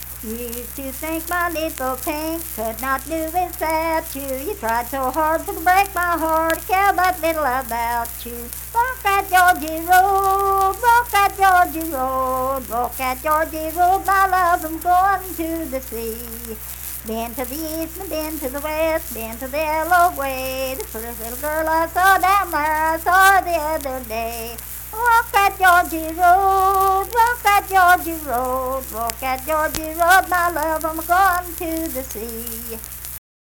Unaccompanied vocal music performance
Verse-refrain 2d(2) & Rd(2).
Dance, Game, and Party Songs
Voice (sung)